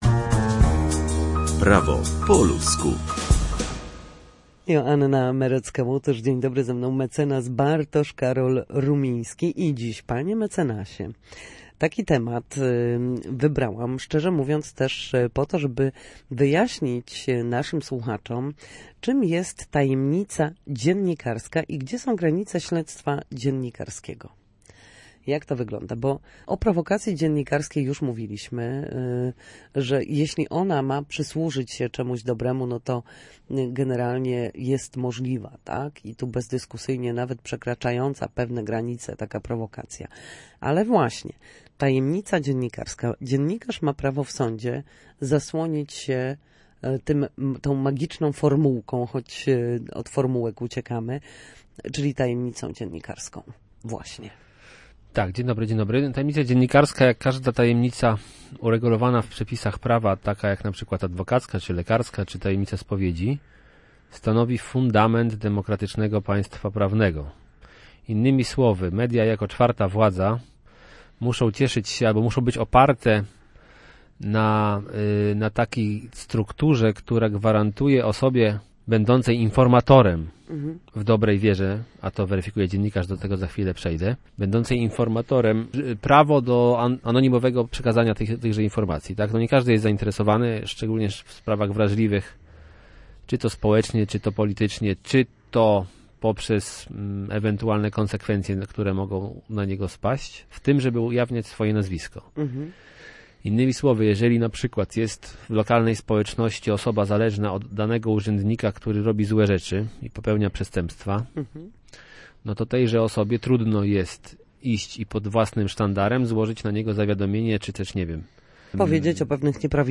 W każdy wtorek o godzinie 13:40 na antenie Studia Słupsk przybliżamy Państwu meandry prawa. W naszej audycji prawnicy odpowiadają na jedno, konkretne pytanie dotyczące zachowania w sądzie lub podstawowych zagadnień prawnych.